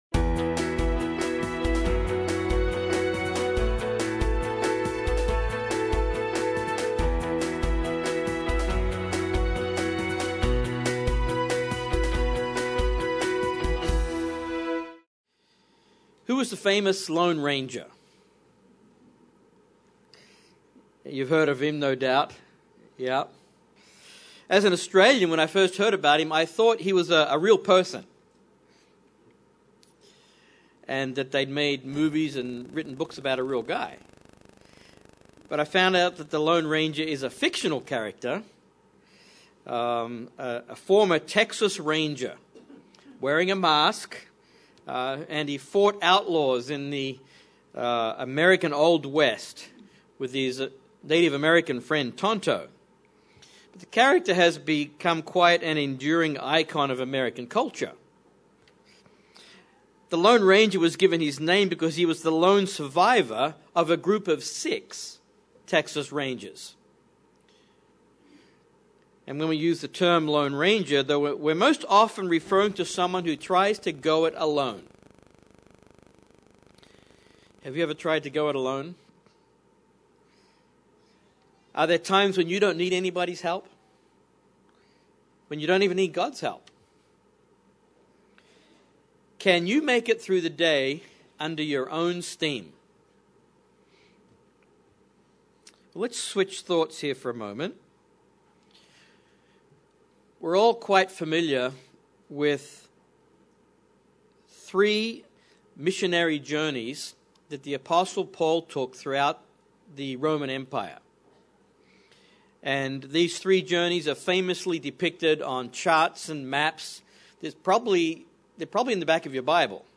Are you fighting against God? This sermon looks at the apostle Paul's journey to conversion and four points to consider.